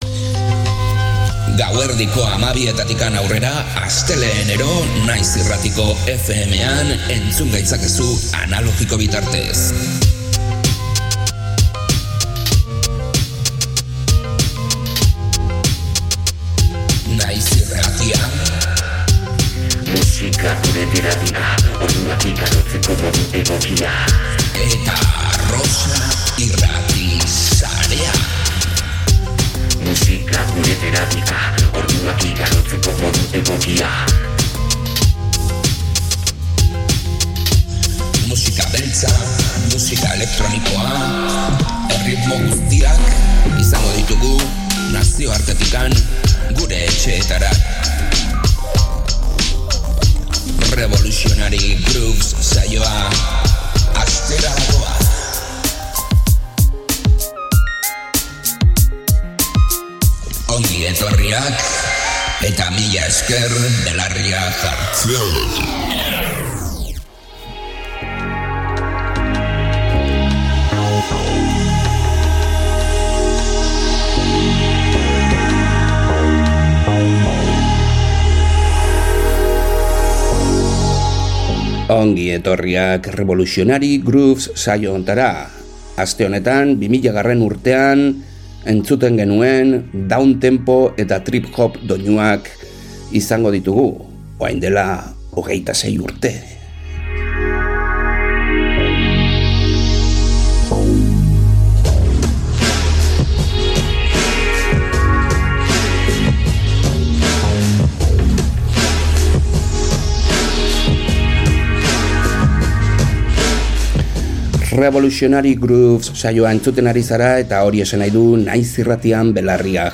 2000.urteko downtempo musika